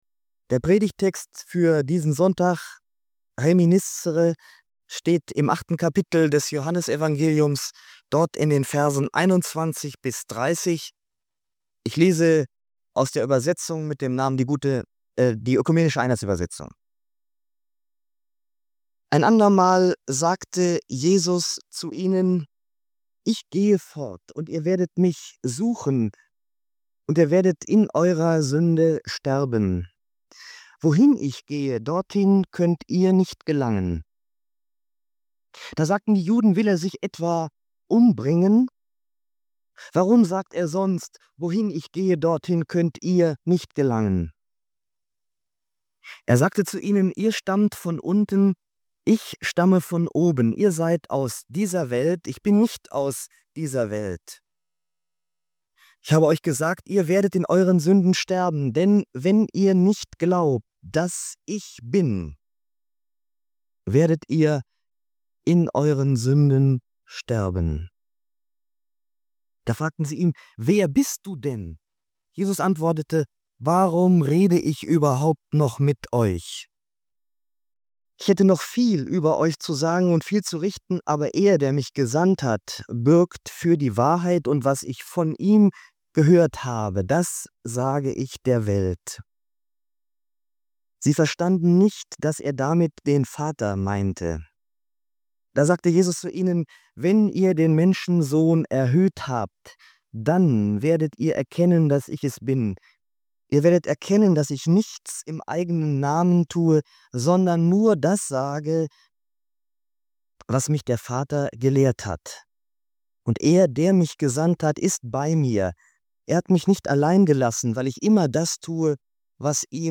In dieser Predigt zum Sonntag Reminiscere steht eine existenzielle Frage im Mittelpunkt: Wie möchten wir sterben?